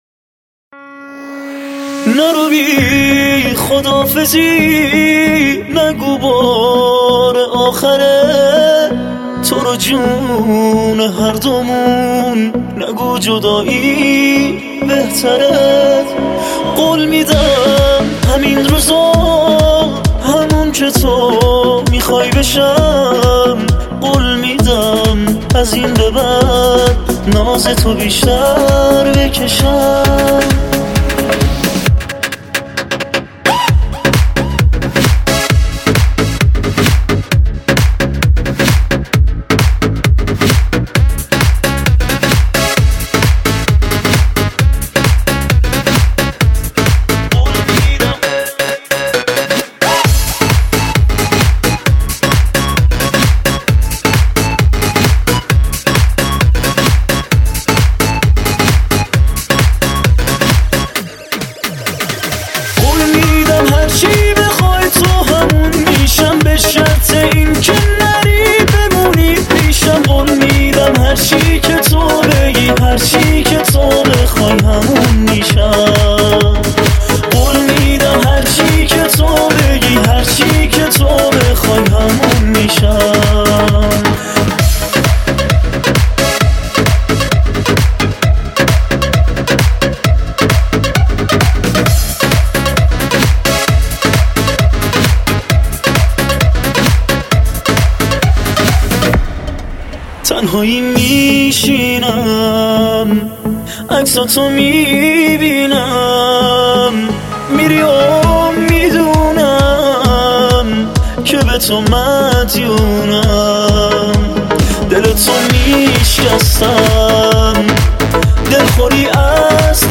موزیک ایرانی